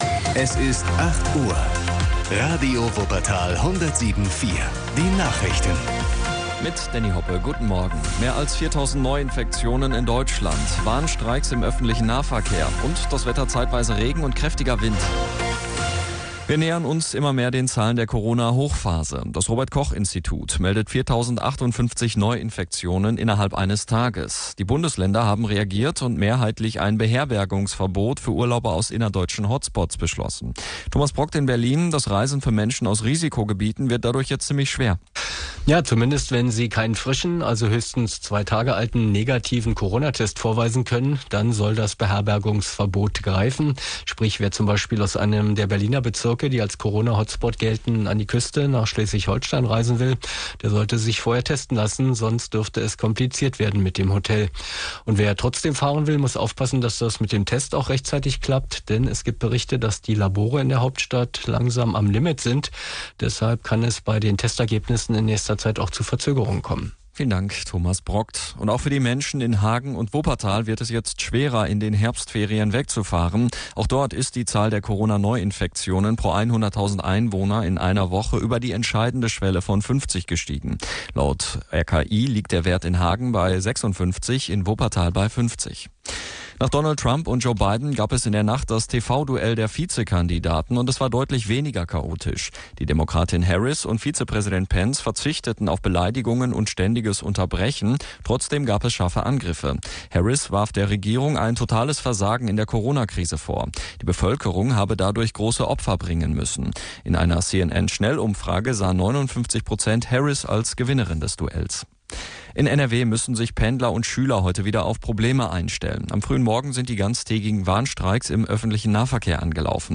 doppeldeckerbus-mitschnitt.mp3